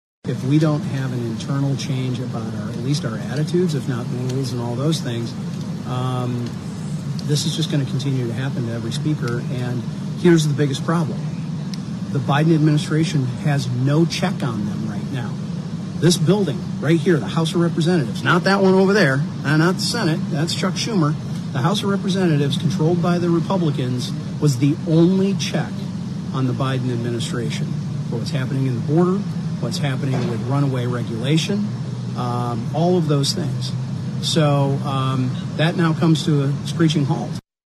Zeeland Republican Bill Huizenga said that this move is a mistake, and right now, the Lower Chamber is run by lions who just keep on eating trainers.